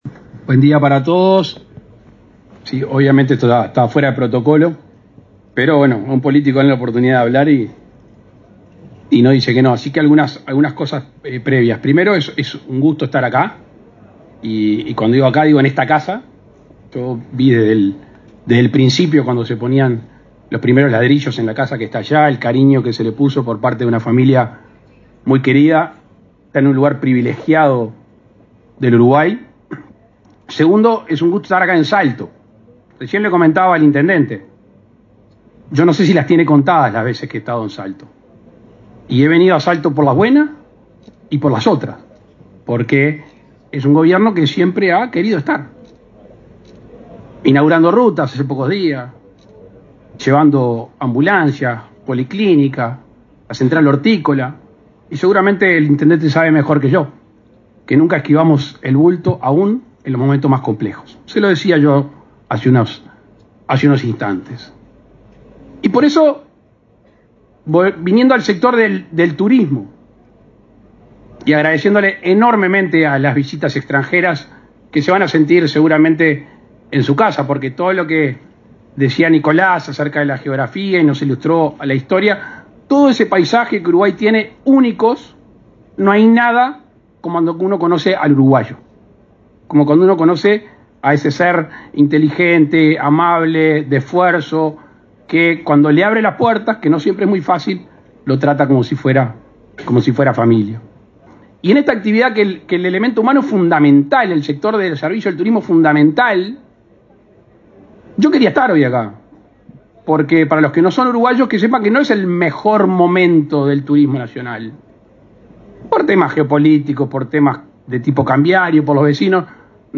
Palabras del presidente Luis Lacalle Pou
Este miércoles 4 en Salto, el presidente de la República, Luis Lacalle Pou, participó en la apertura del 21.° Encuentro Internacional del Turismo